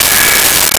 Megaphone Feedback 03
Megaphone Feedback 03.wav